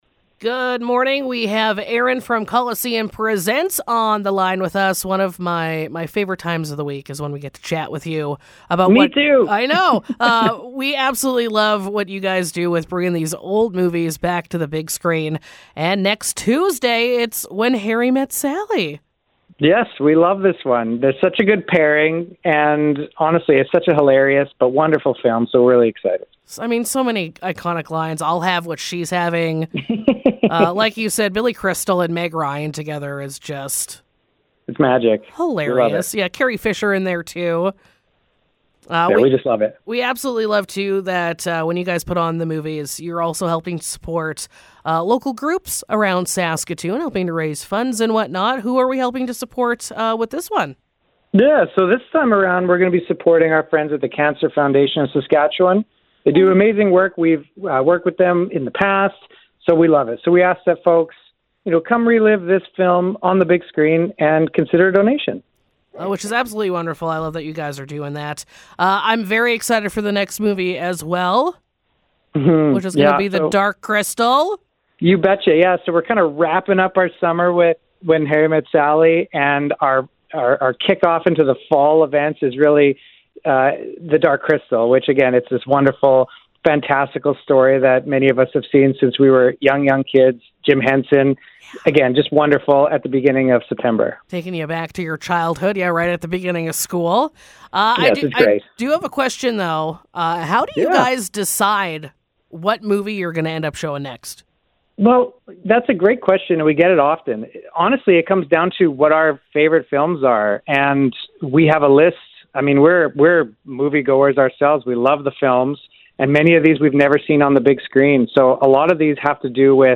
Interview: Colosseum Presents Next Feature